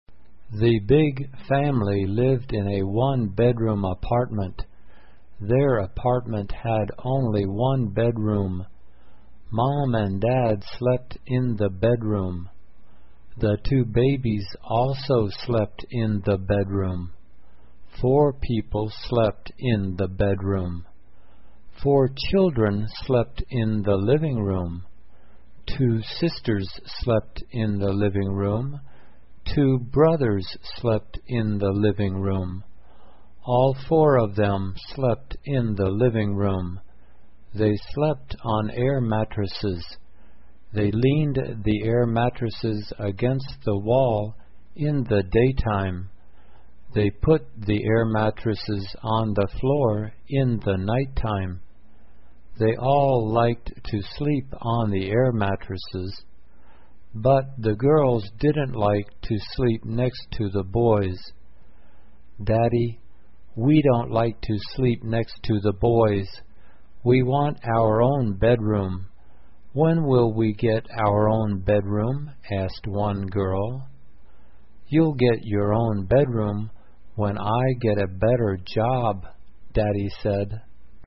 慢速英语短文听力 小型公寓 听力文件下载—在线英语听力室